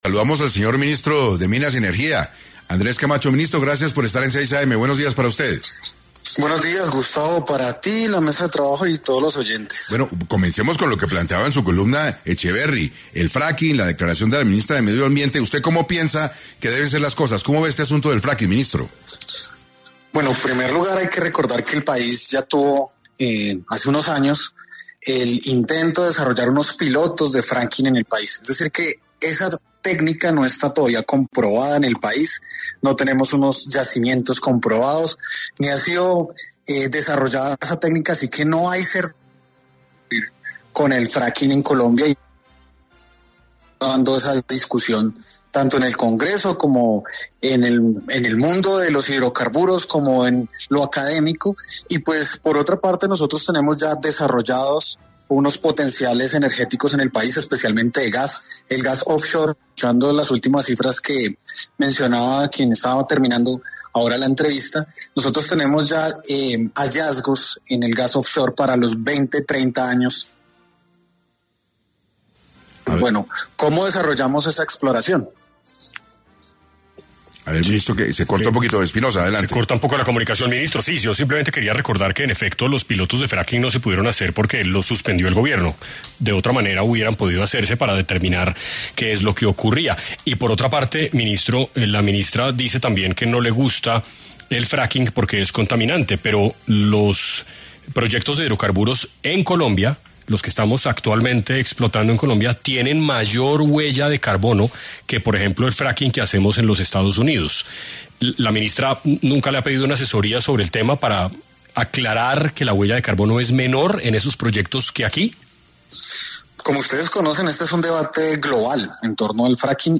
En 6AM estuvo Andrés Camacho, ministro de Minas, quien habló sobre un posible apagón eléctrico y racionamiento en el país.